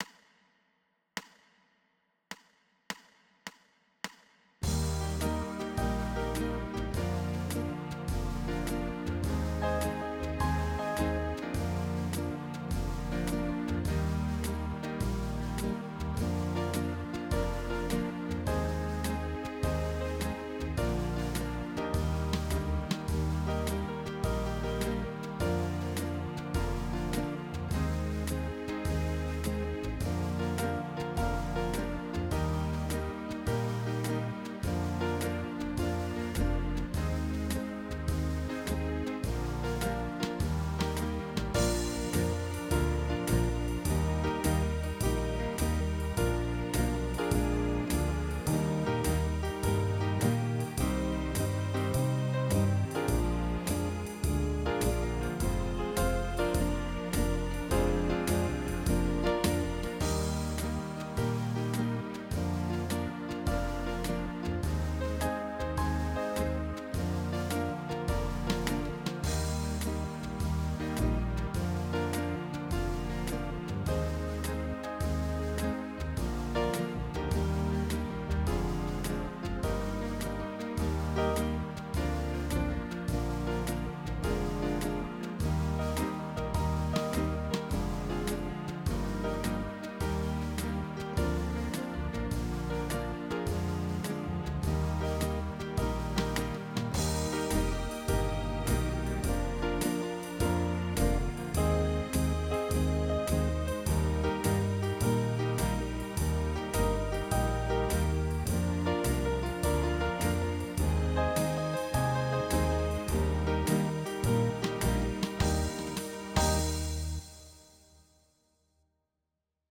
Trombone